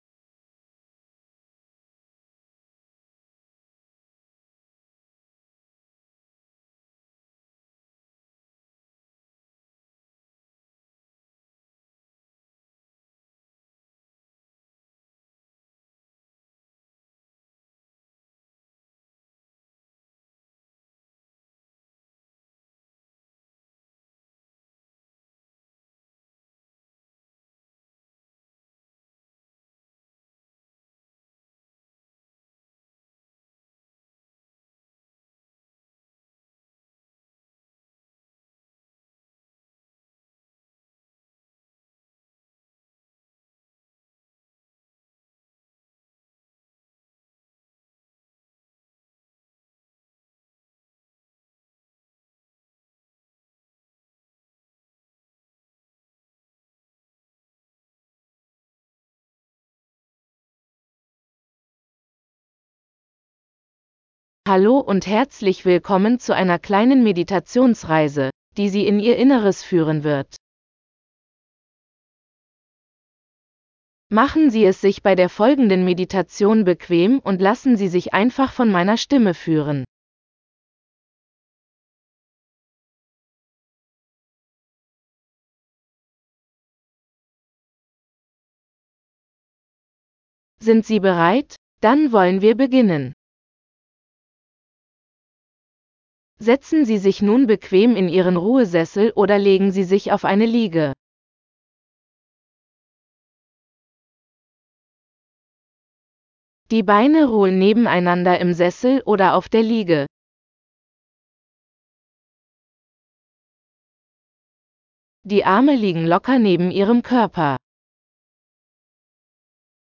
Halten Sie Ihre Konzentration an diesen Stellen während Sie sich von der Musik berieseln lassen.